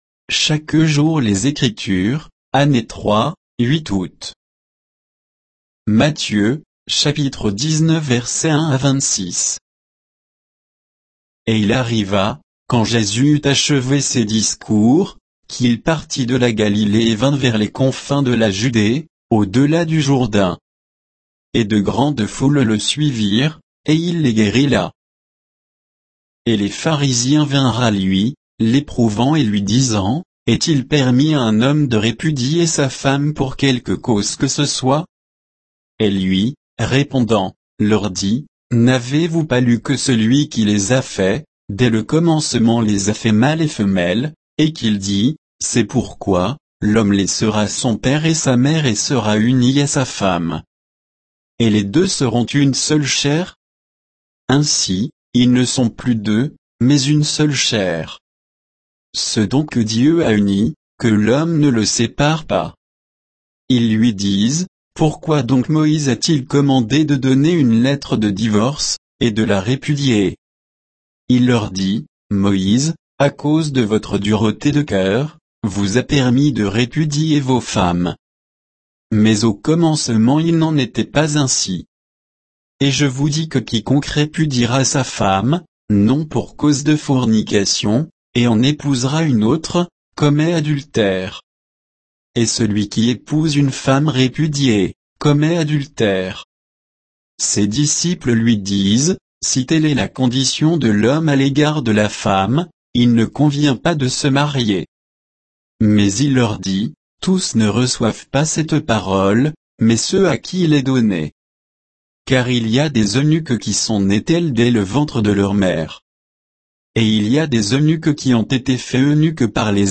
Méditation quoditienne de Chaque jour les Écritures sur Matthieu 19, 1 à 26